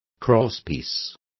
Complete with pronunciation of the translation of crosspieces.